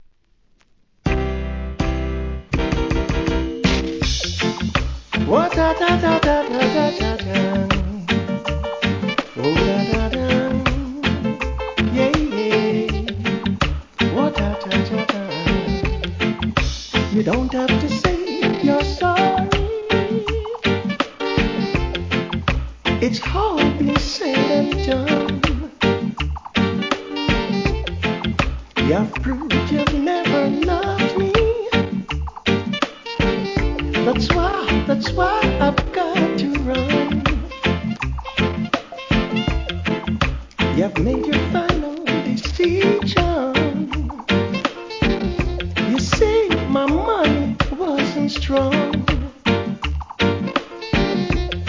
REGGAE
リメイクRHYTHM!!